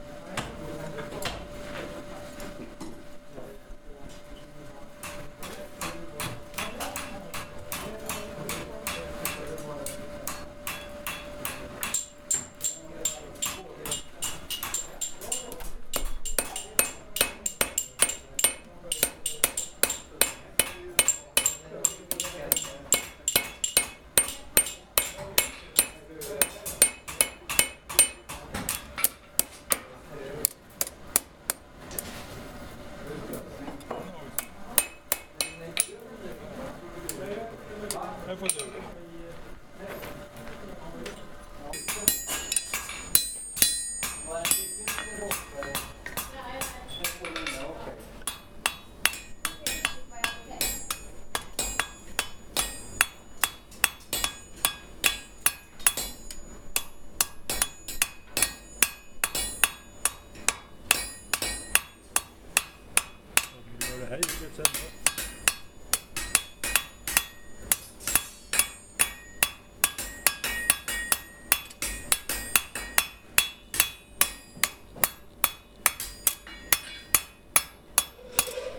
blacksmith-2.ogg